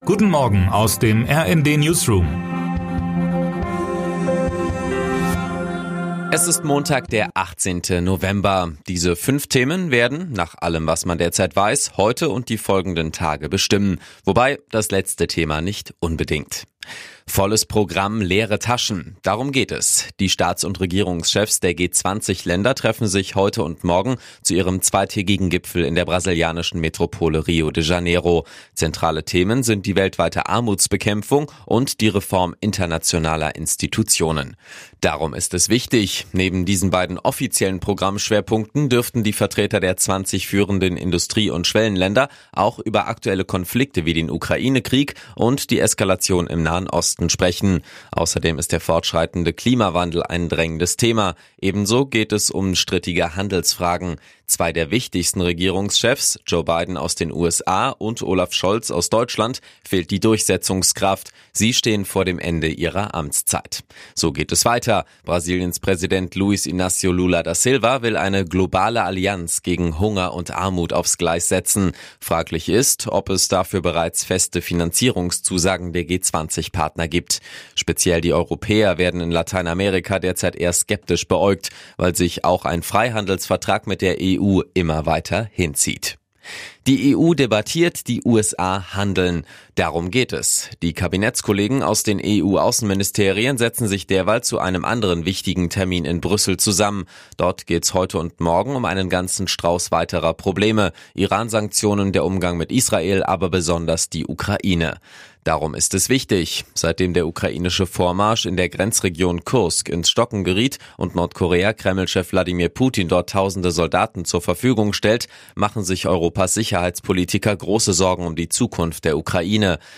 Guten Morgen aus dem RND-Newsroom
Nachrichten